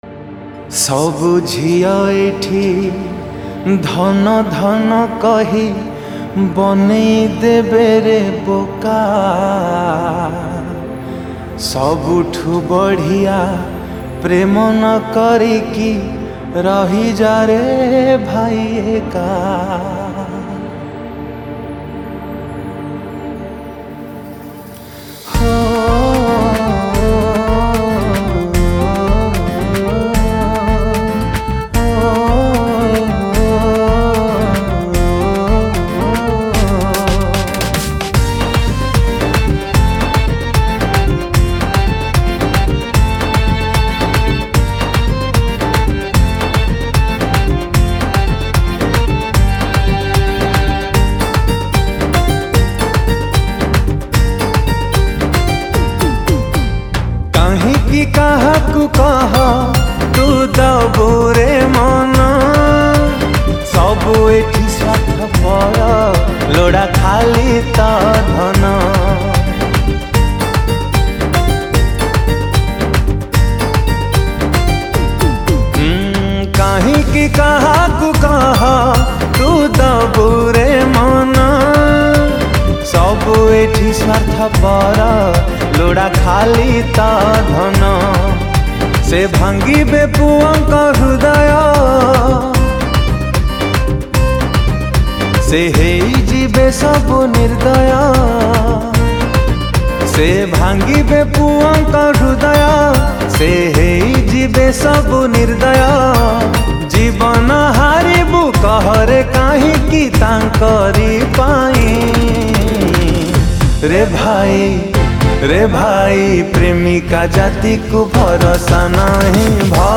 Song Type :Sad